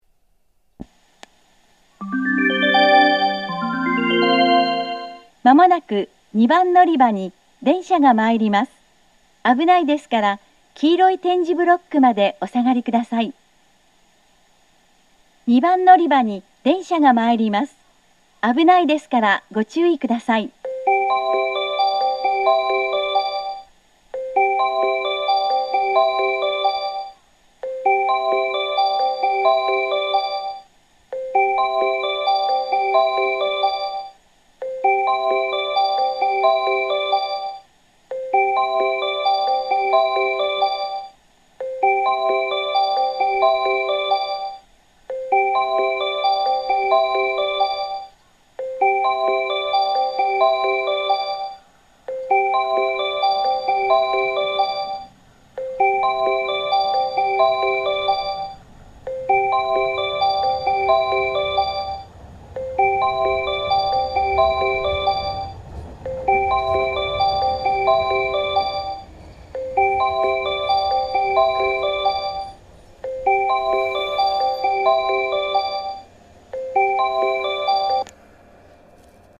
（女性）
接近放送 接近メロディーは16.4コーラス鳴っています。
どちらの番線も電車が停車するまで流れるので、停車列車の方が長く鳴ります。